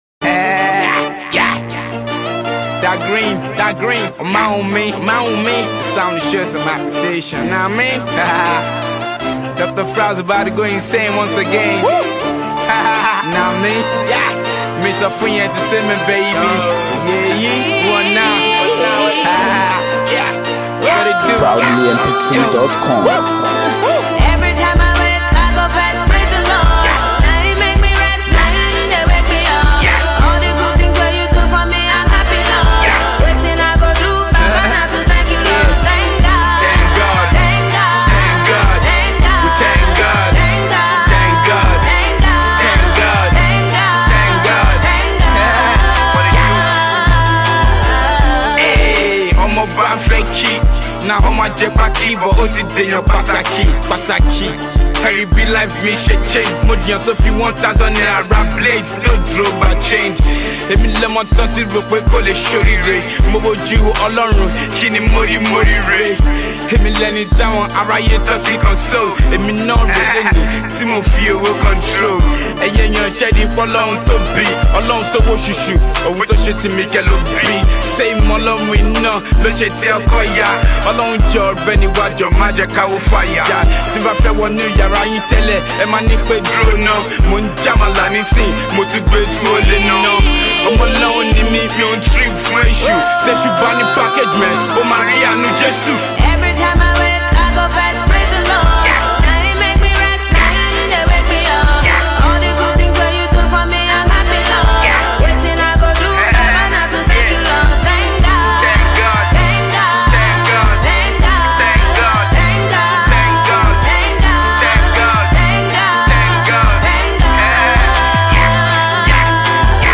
we commemorate the deceased versatile indigenous rapper